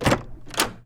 DoorOpenSoundEffect.wav